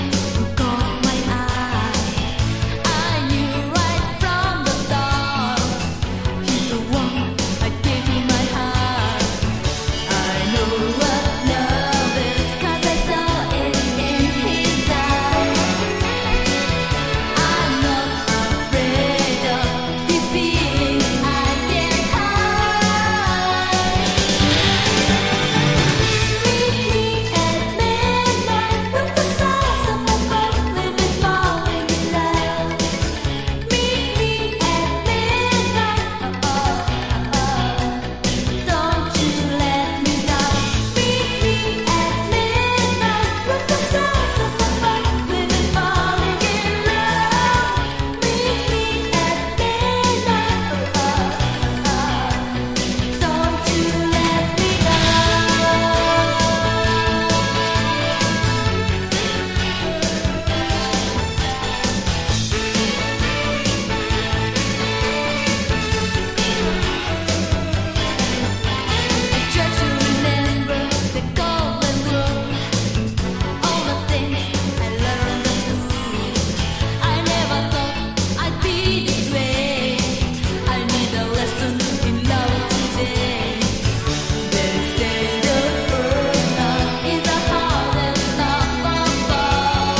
シンセとリズムマシンメインの80Sダンスサウンドに彩られた全編英語詞による逆輸入型ポップス。
# POP# 和モノ